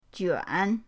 juan3.mp3